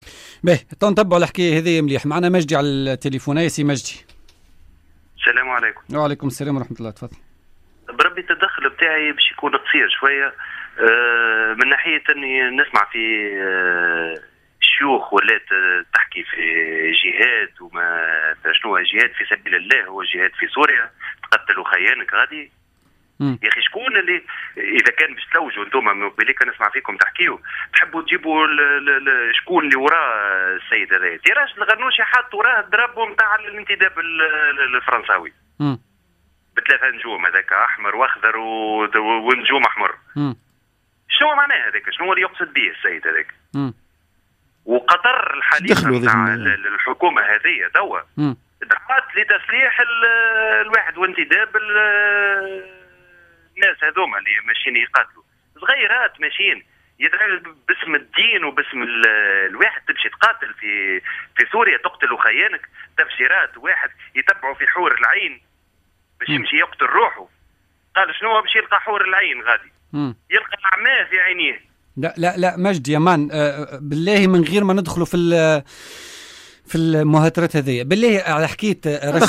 فـــــــــــــي مداخلة له على إكبرس ف م ..